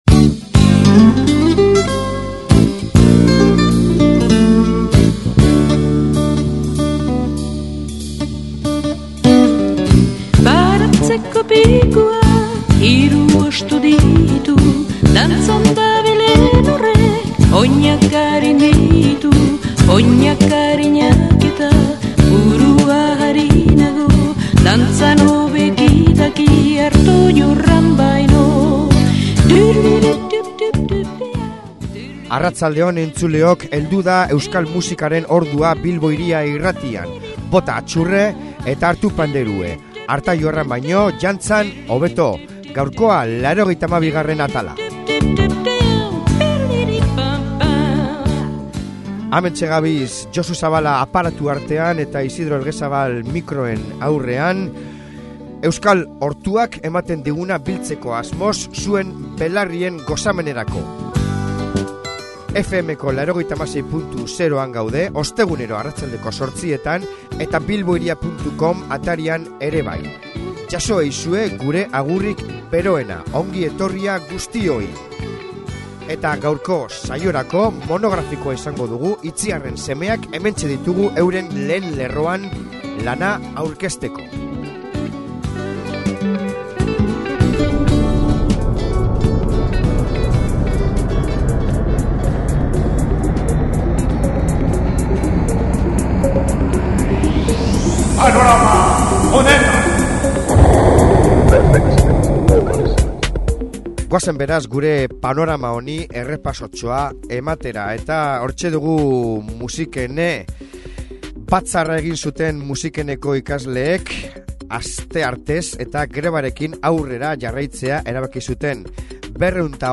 “Radikals”…nostalgia, ska, punk eta jaia mungiarra!